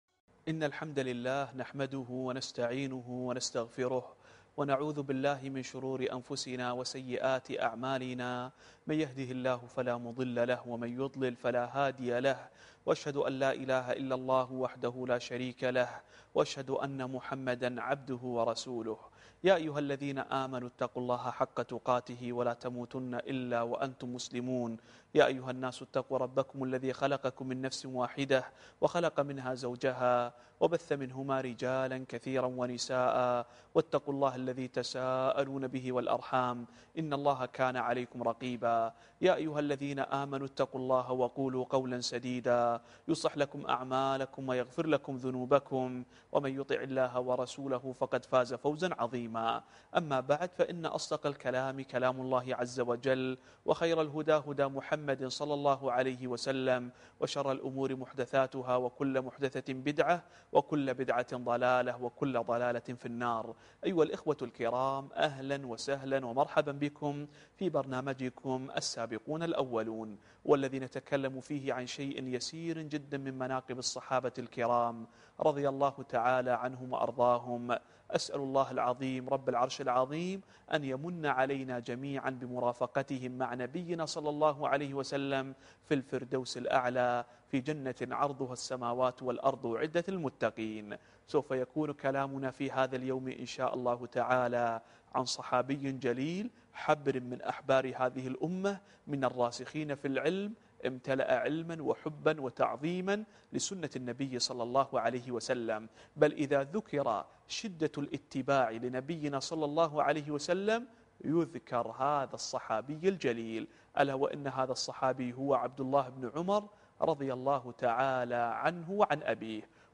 الدرس الرابع والعشرون